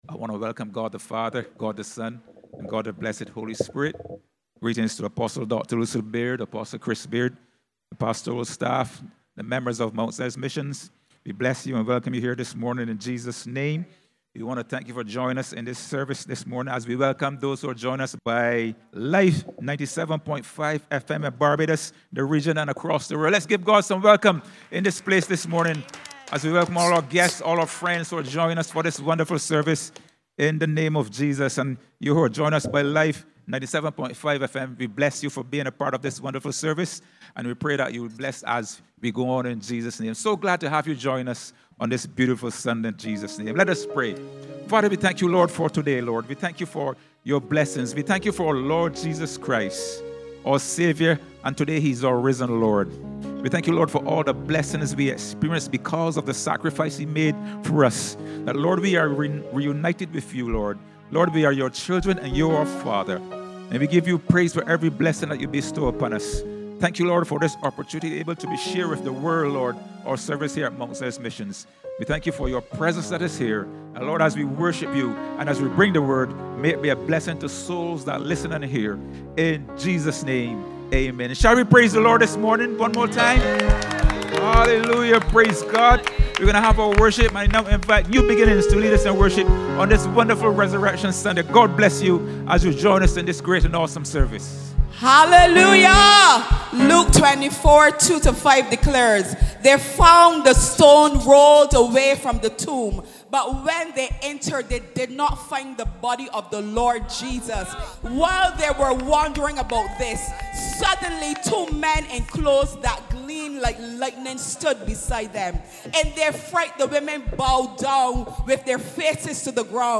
In God's Glory Church Service April 20th 2025